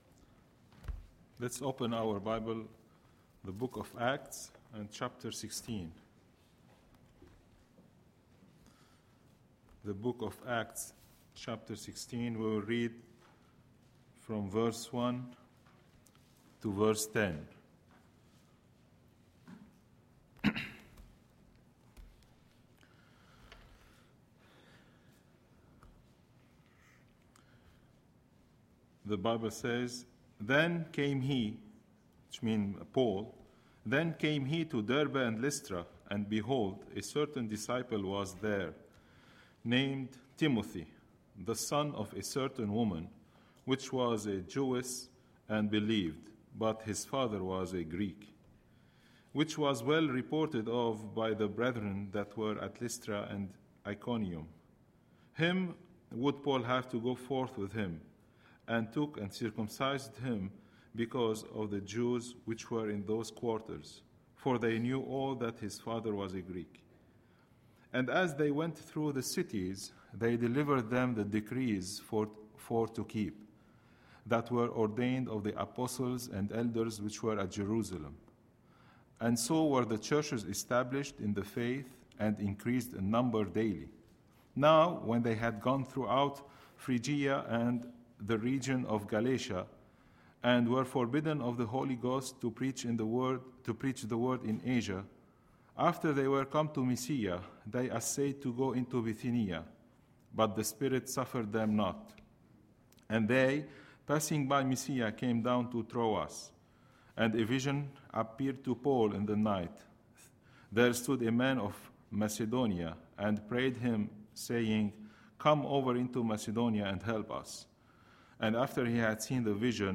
Tuesday, September 27, 2011 – Evening Message